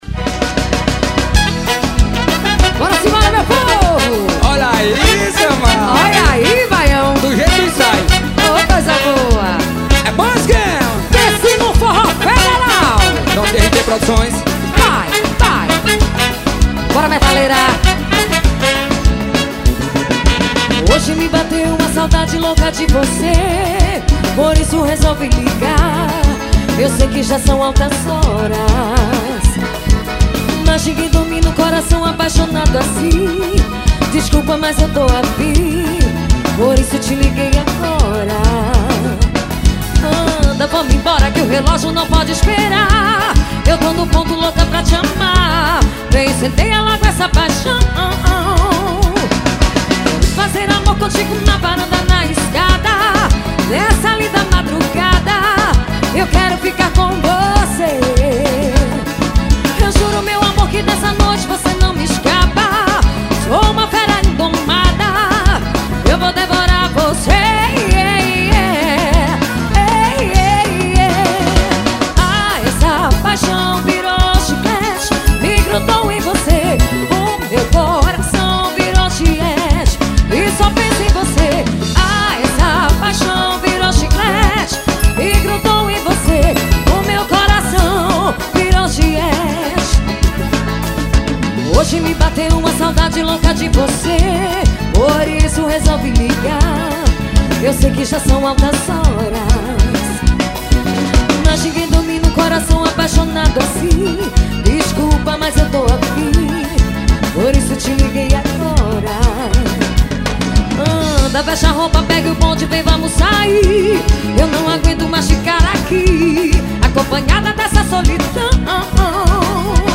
forró.